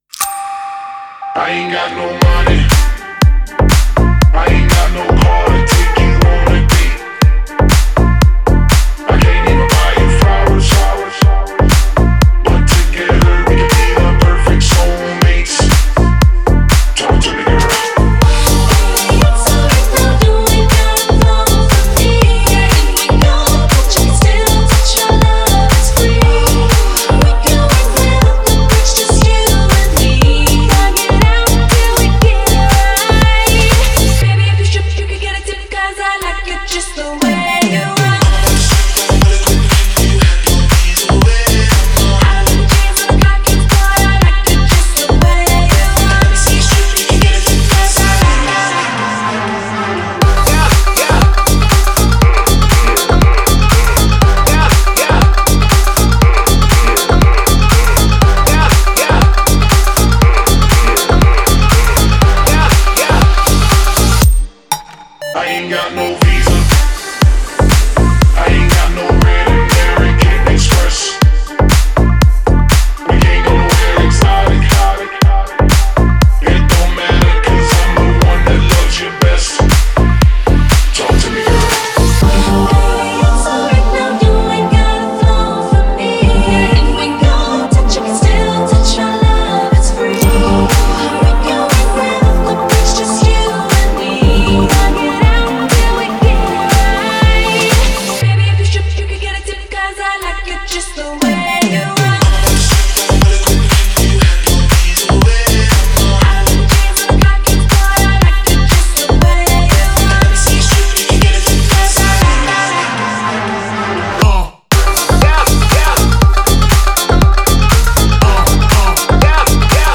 это зажигательная песня в жанре R&B и хип-хоп